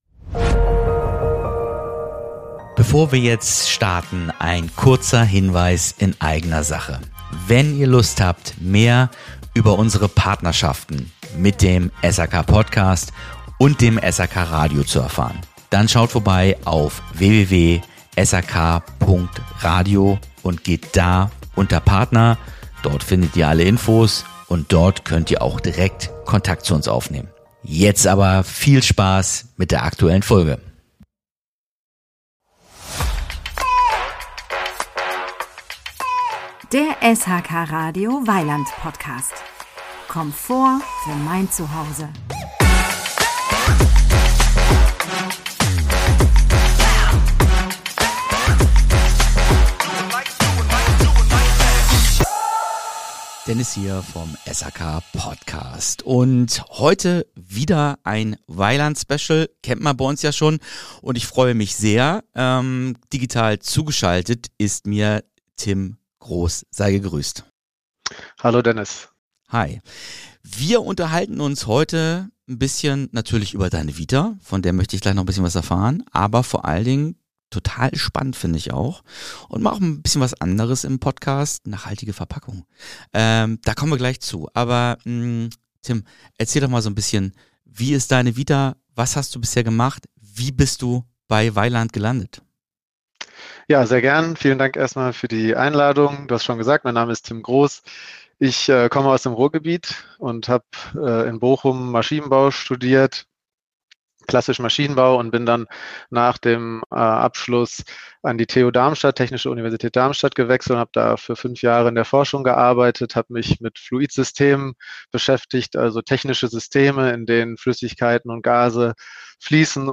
Ein Gespräch über Nachhaltigkeit, Effizienz – und darüber, wie ein “unsichtbares” Thema zum Aushängeschild für Innovation werden kann.